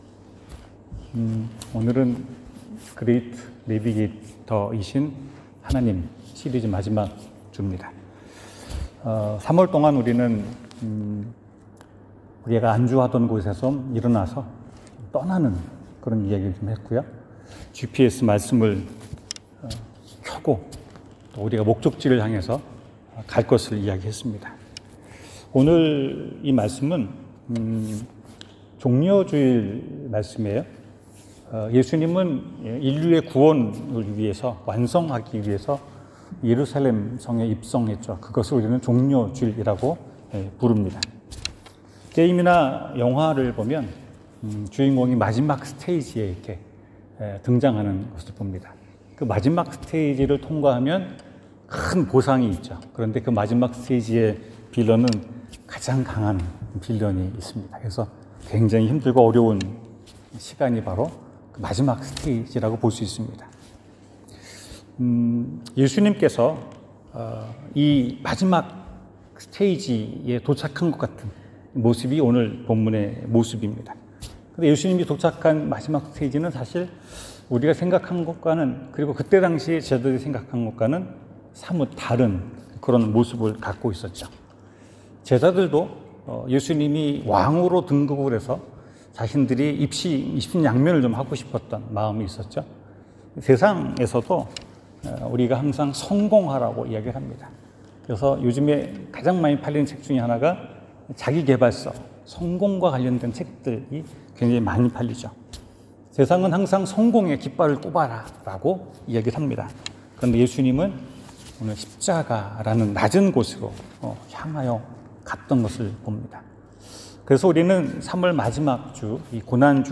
성경: 누가복음 19:28-40 설교